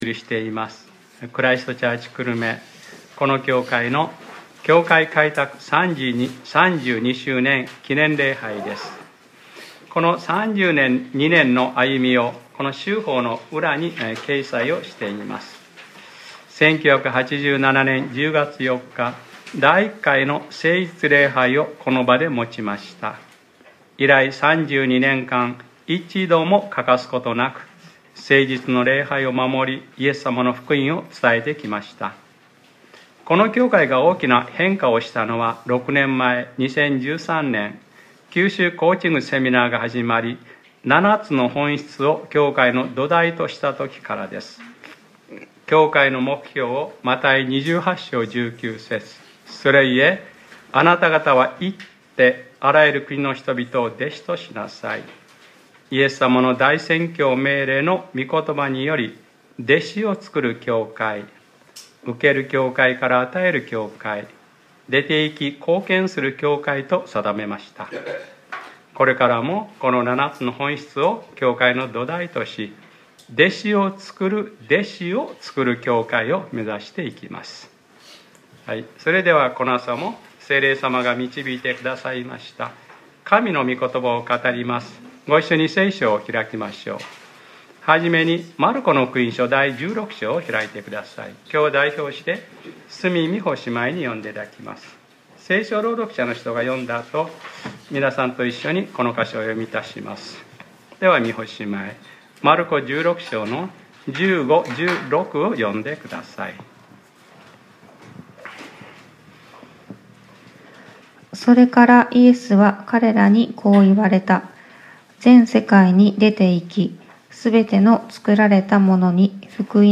2019年10月06日（日）礼拝説教『集まる教会・出て行く教会』